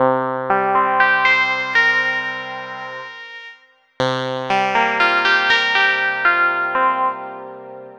Nite Mission Lead.wav